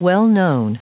Prononciation du mot well-known en anglais (fichier audio)
Prononciation du mot : well-known